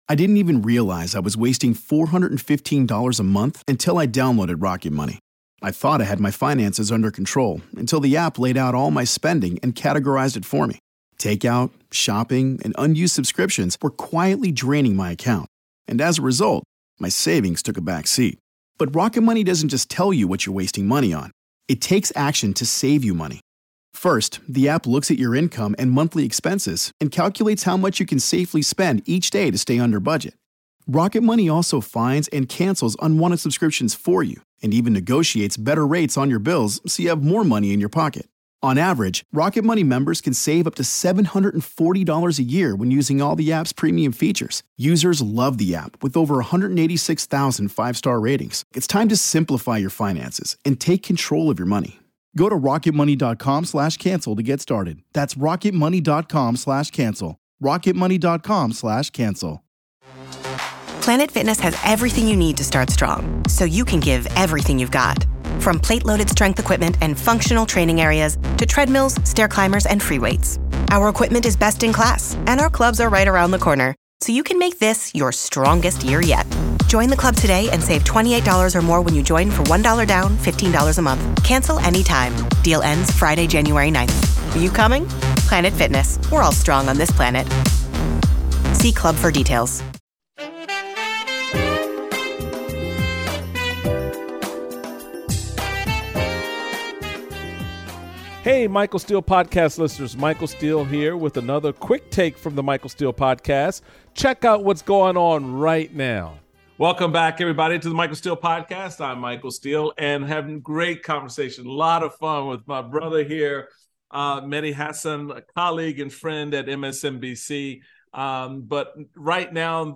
Mehdi Hasan speaks with Michael Steele about the art of debate. The pair discuss the importance of knowing your audience, showing receipts and coming prepared.